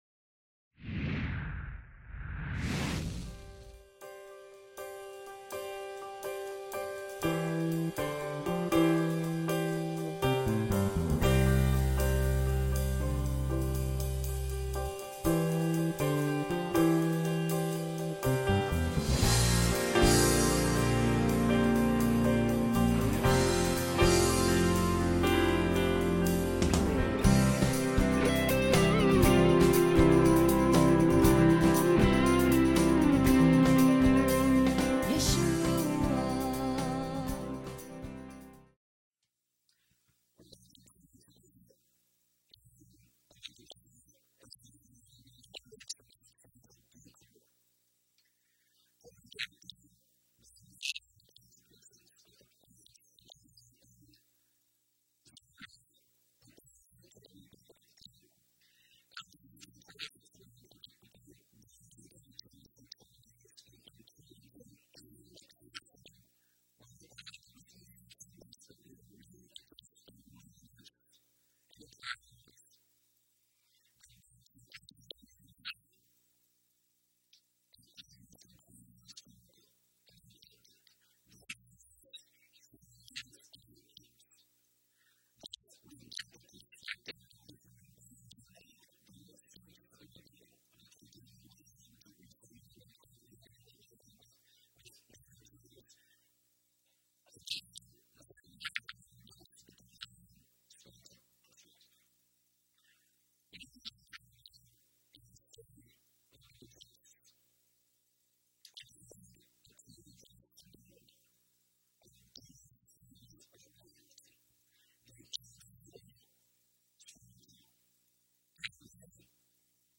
Lesson 9 Ch5 - Torah Class